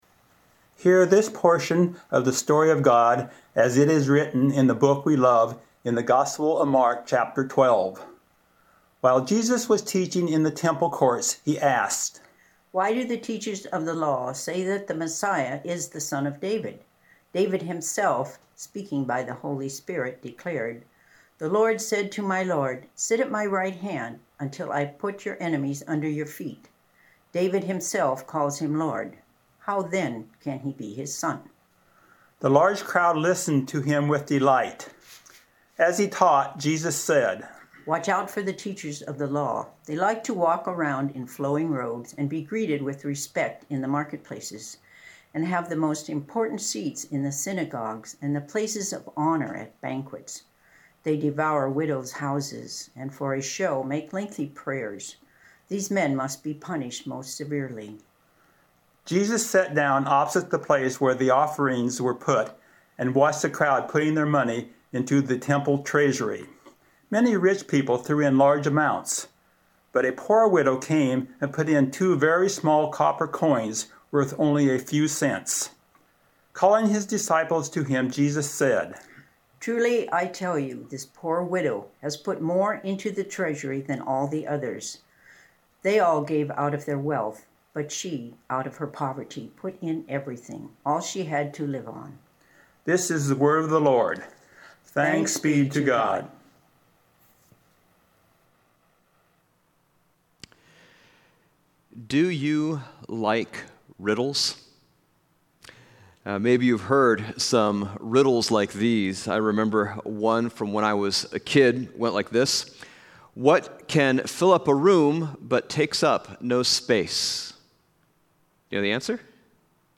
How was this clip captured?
Passage: Mark 12:35-44 Service Type: Sunday Morning